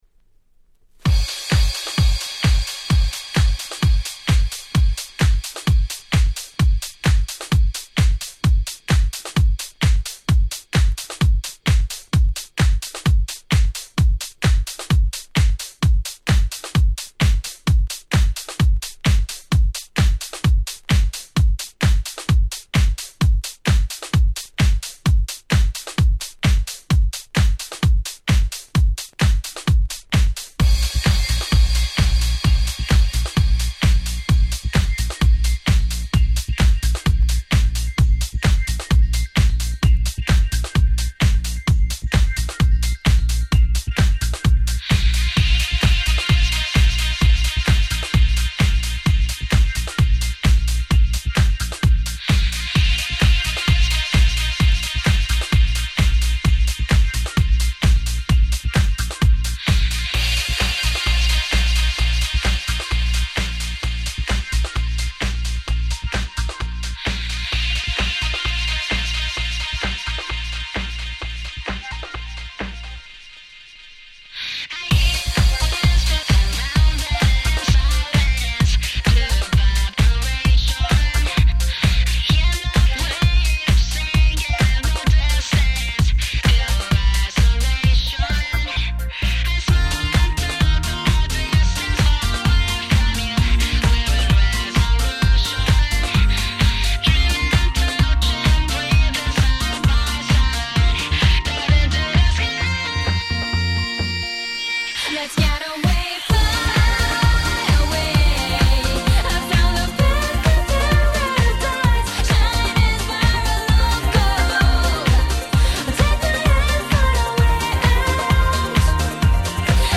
Super Hit Vocal House !!